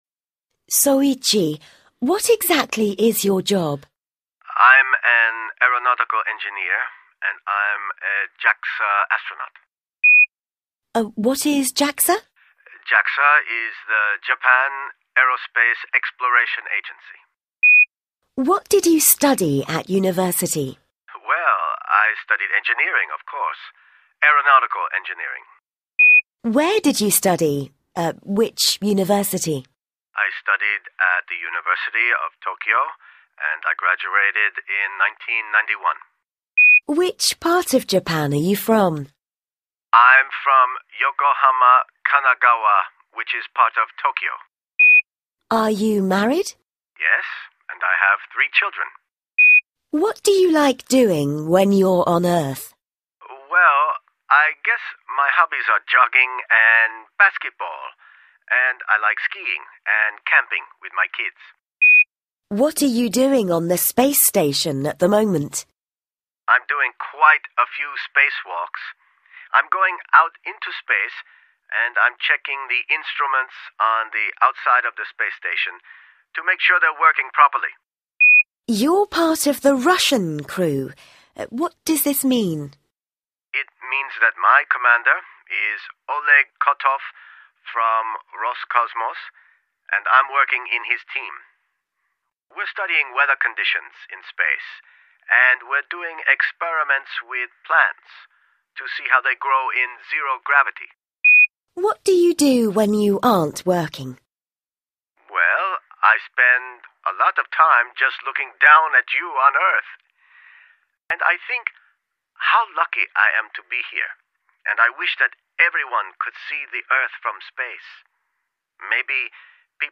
Пример диалога Present Continuous